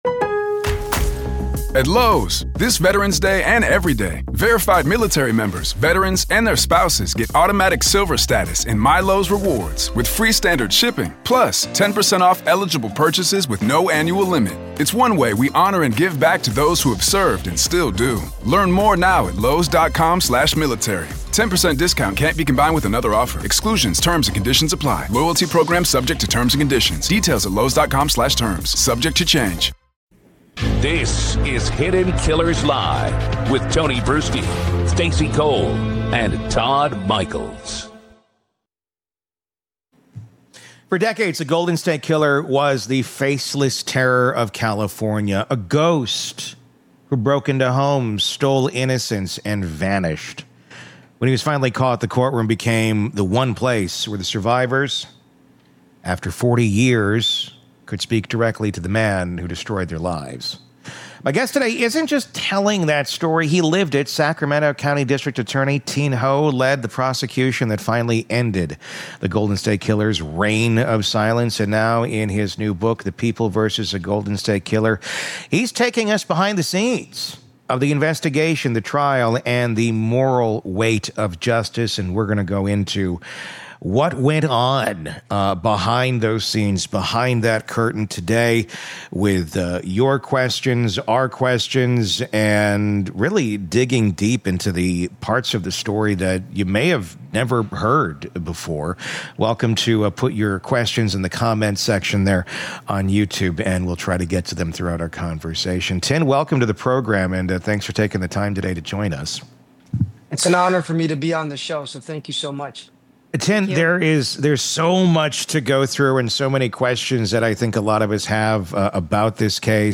It’s a conversation about justice, redemption, and the moral courage it takes to stand in the face of real evil — and win.